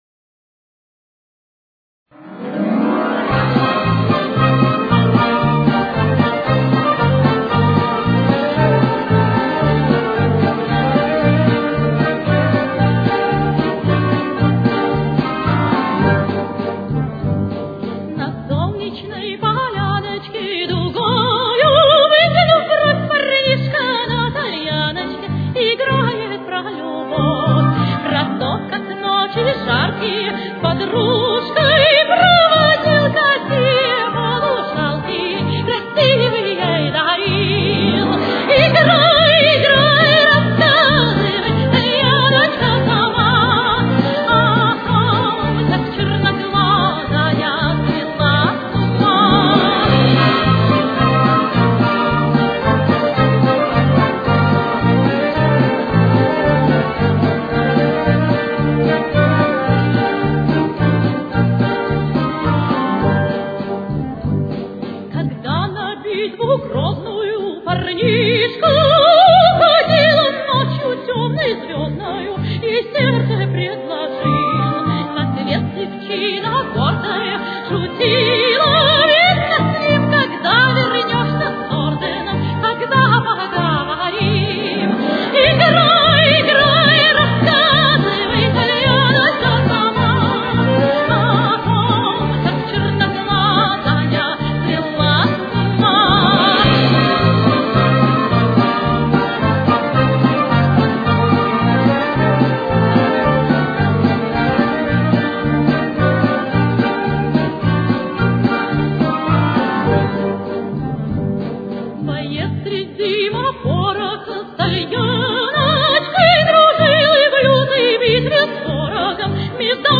Соль минор. Темп: 102.